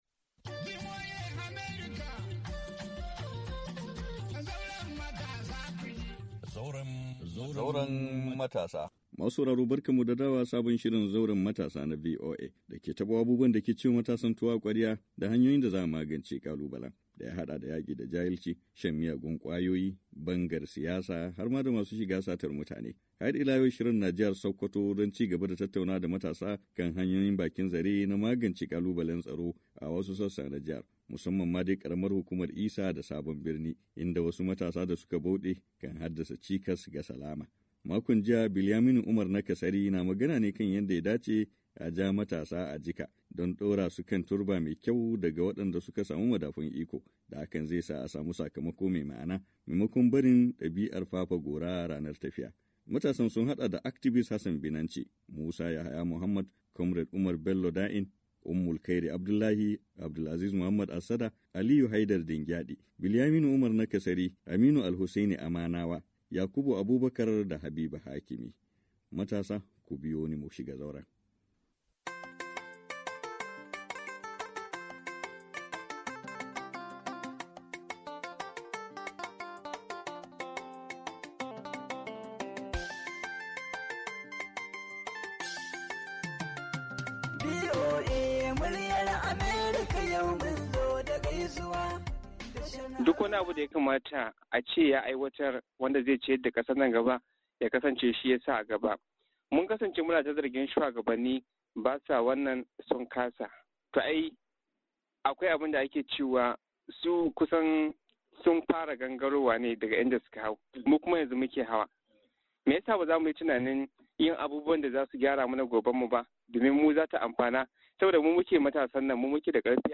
ABUJA, NIGERIA - Shirin na wannan makon ya ci gaba da tattaunawa da matasa a jihar Sokoto da ke arewa maso yammacin Najeriya kan batun kalubalen tsaro da ke addabar wasu kananan hukumomin jihar.